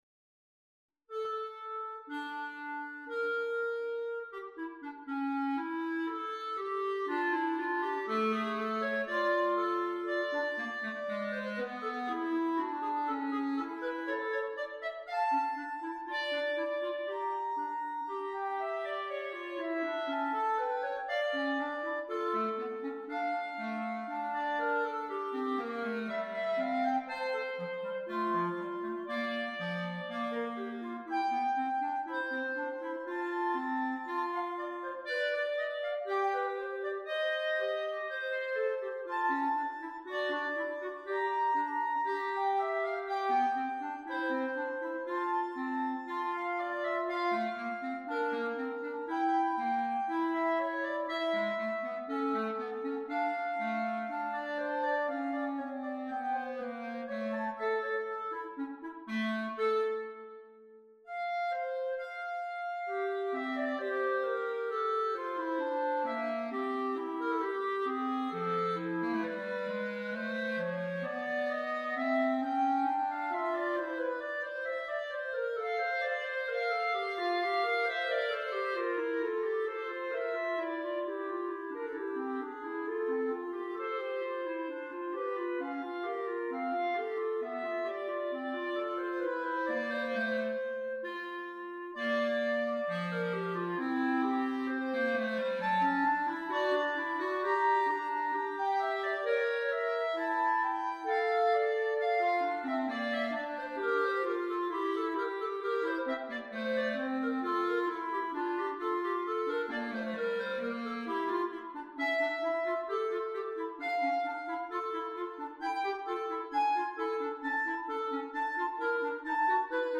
per due clarinetti in Sib